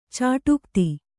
♪ cāṭūkti